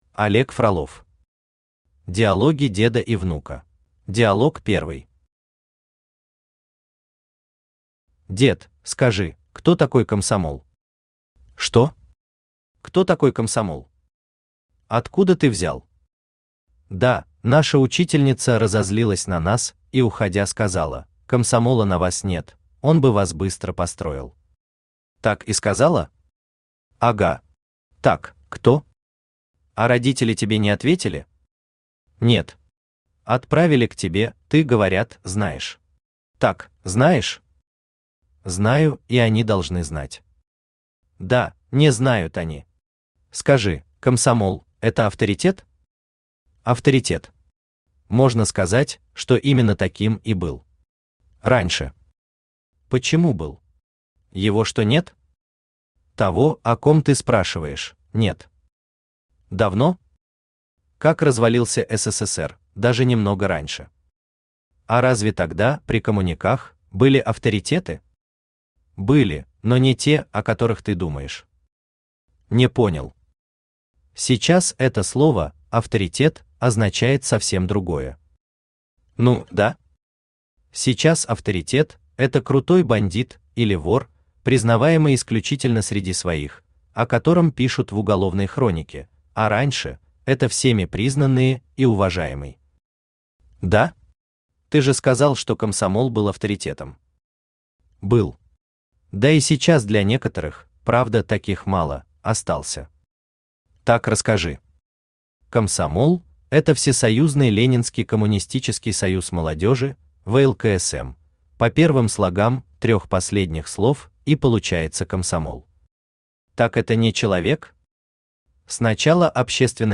Аудиокнига Диалоги деда и внука | Библиотека аудиокниг
Aудиокнига Диалоги деда и внука Автор Олег Васильевич Фролов Читает аудиокнигу Авточтец ЛитРес.